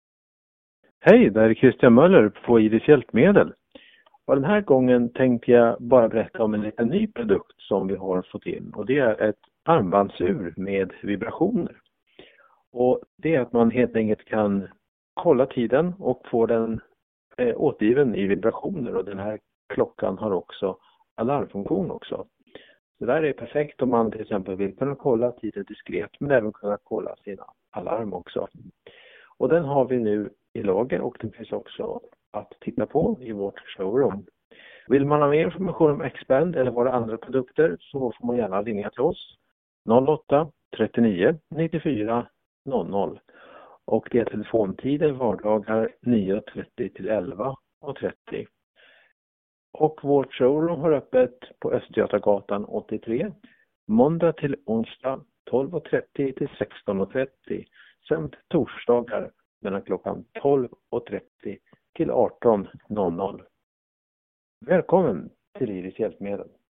Lyssna gärna till detta nyhetsinslag om det vibrerande armbandsuret xBand hämtat från Läns- och Riksnytt nummer 3, 2025. Håll koll på tiden och dina alarm som vibrationer direkt på handleden.